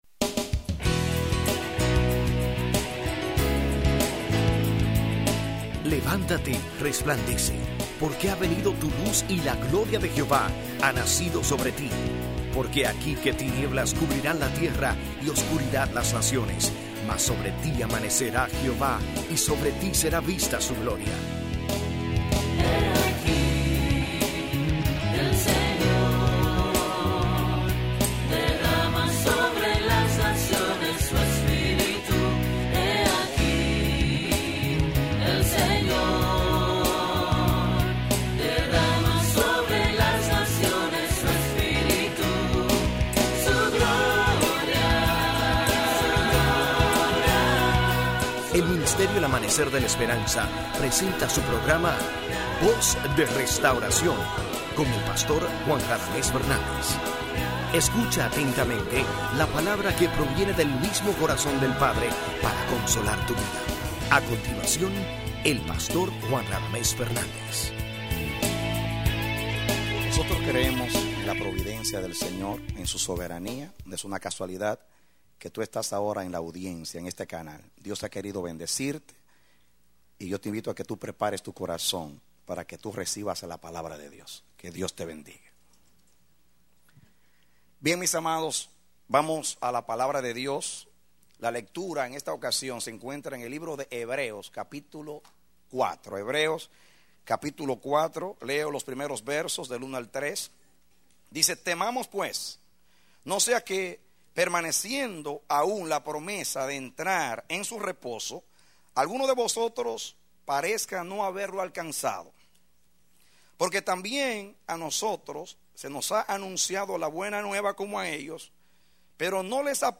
A mensaje from the serie "Mensajes." Predicado Jueves 20 de Octubre, 2016